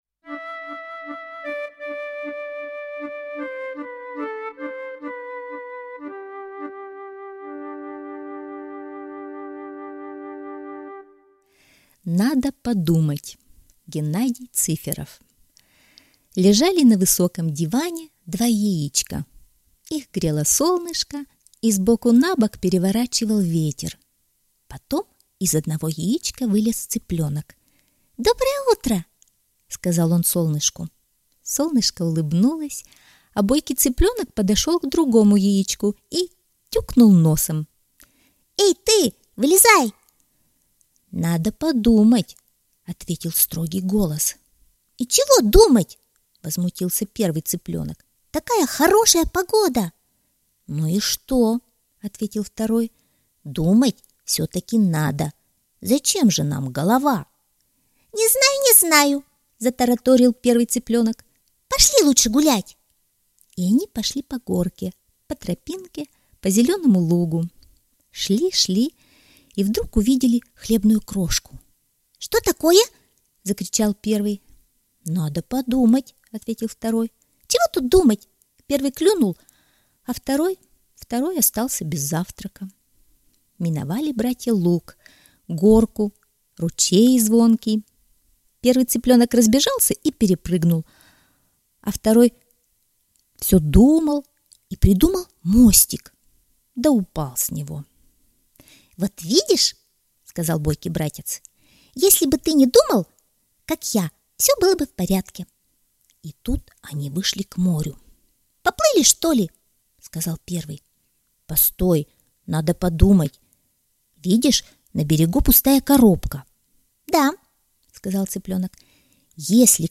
Надо подумать - аудиосказки Цыферова Г.М. Познавательная сказка про то, как важно подумать перед тем, как что-то сделать...